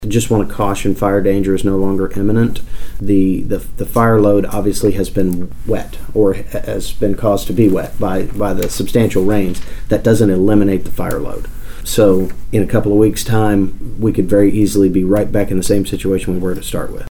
Washington County District 1 Commissioner Mitch Antle says even though the ban has been lifted,